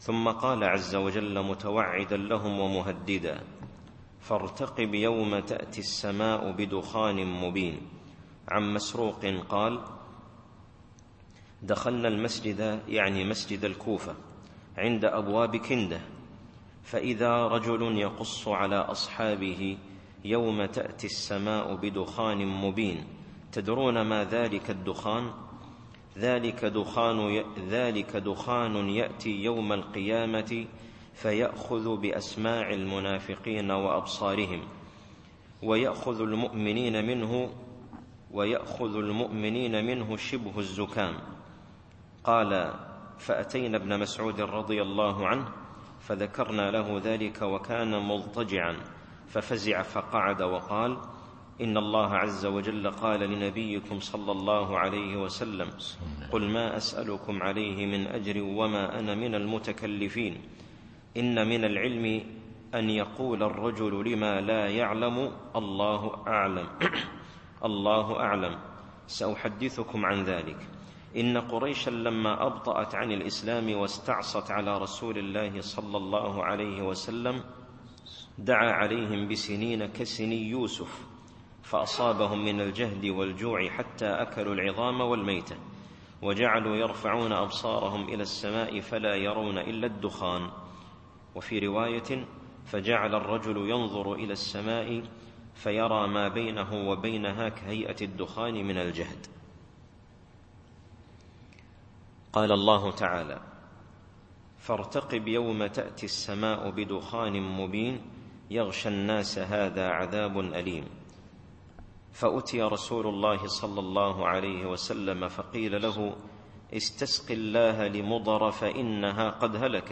التفسير الصوتي [الدخان / 11]